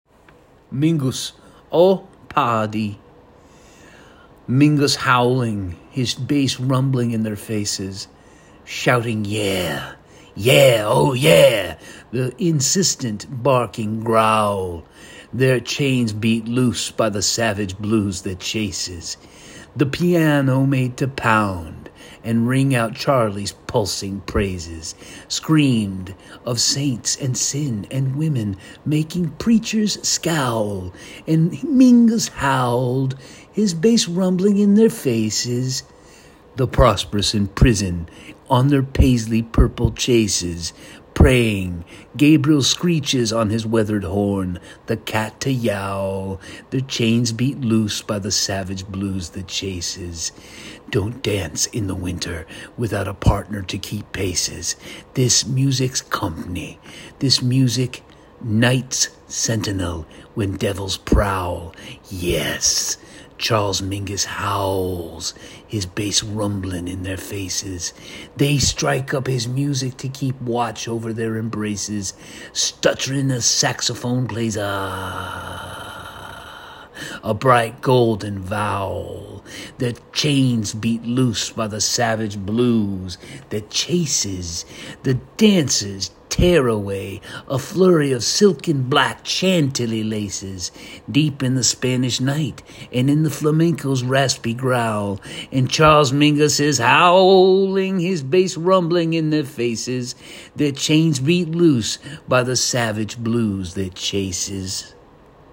The Sunday Poem  is published weekly, and strives to include the poet reading their work.